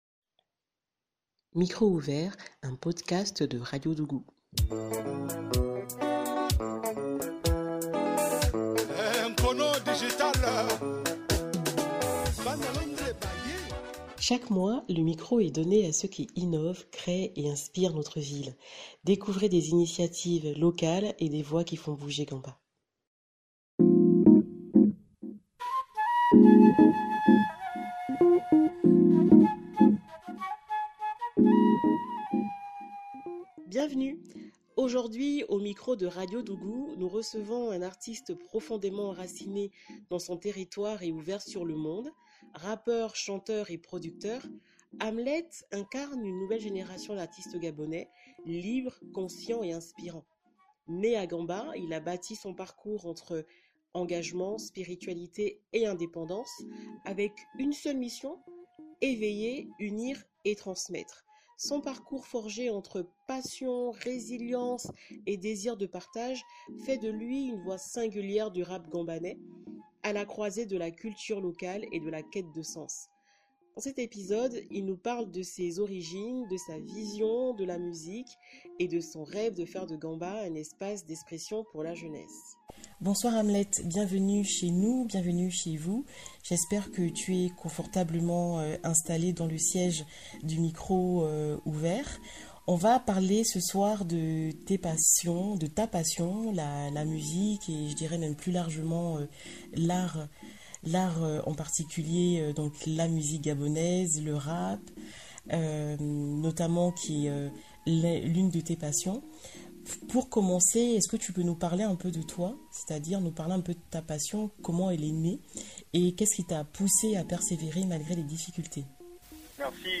Un échange vibrant, authentique et inspirant, au cœur de la vie locale gambanaise.